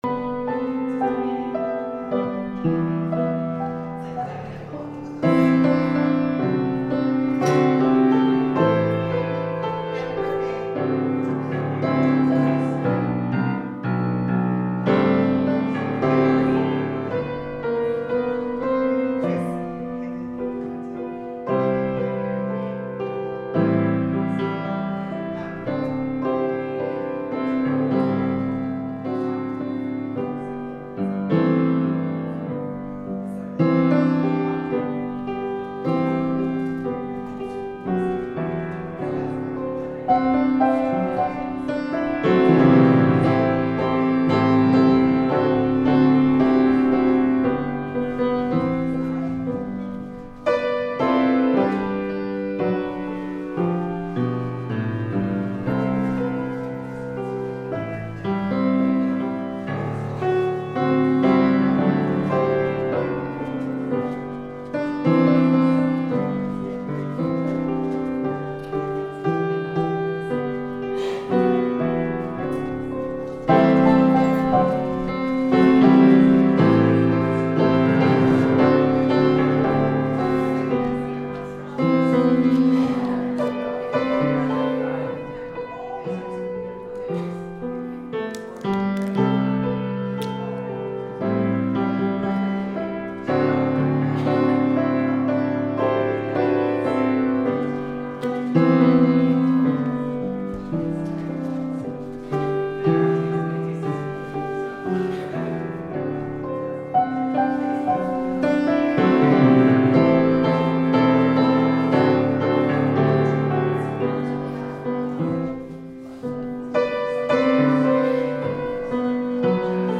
The Saints – Last Trumpet Ministries – Truth Tabernacle – Sermon Library